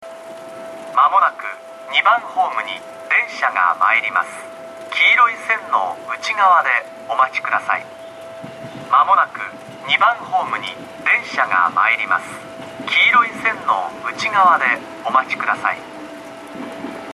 shiraitodai2-sekkin.mp3